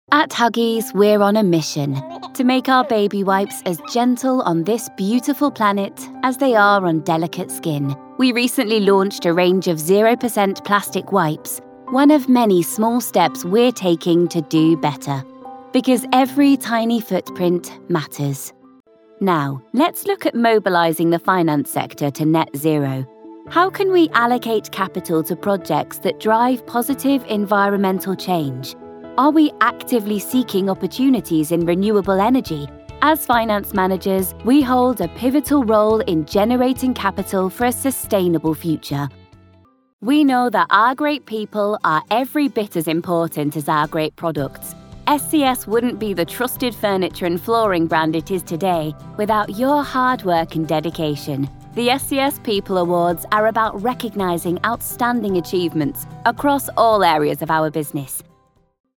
Inglés (Británico)
Natural, Travieso, Versátil, Amable, Cálida
Corporativo